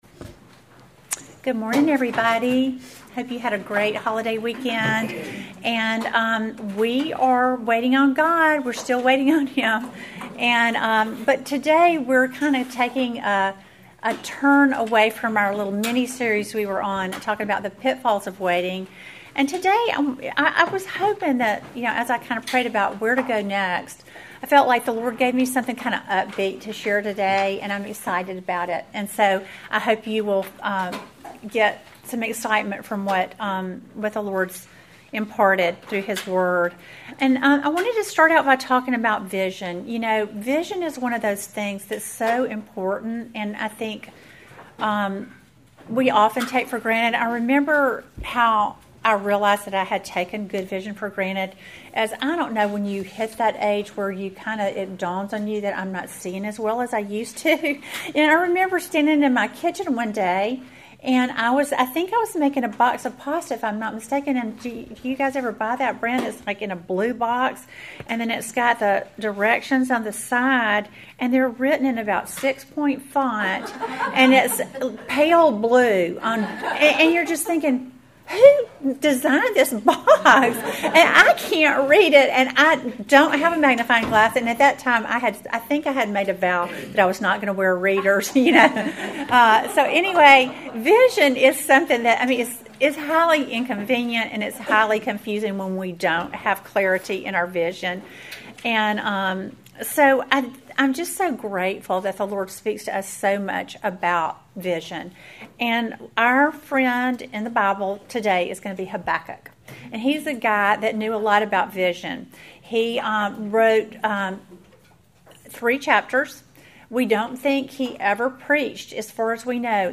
Welcome to the fourteenth lesson in our series WAITING ON GOD!